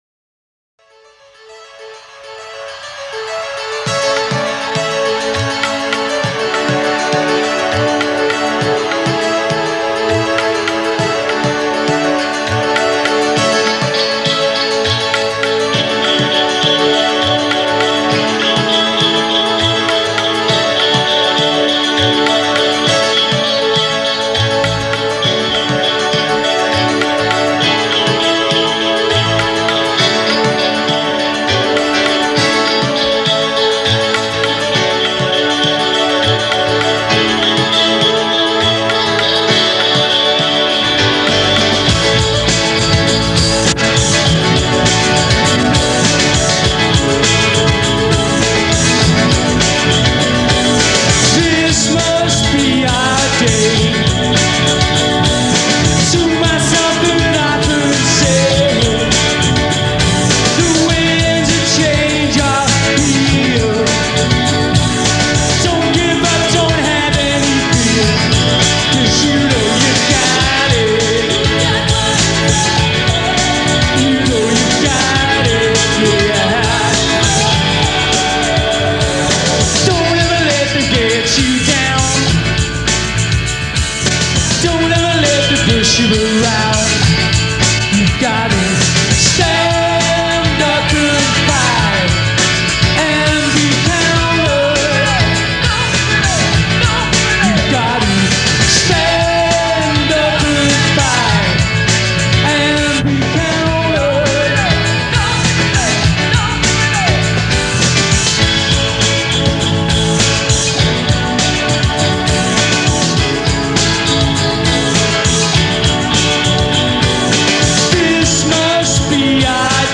recorded March 24, 1991 - Royal Court, Liverpool.